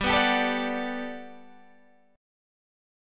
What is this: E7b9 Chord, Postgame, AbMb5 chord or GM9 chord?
GM9 chord